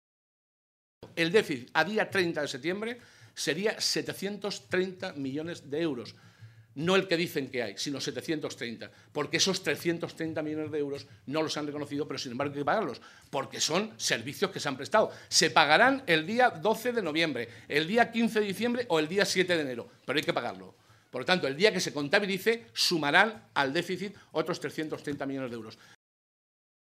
José Molina, coordinador de Economía del Grupo Parlamentario Socialista
Cortes de audio de la rueda de prensa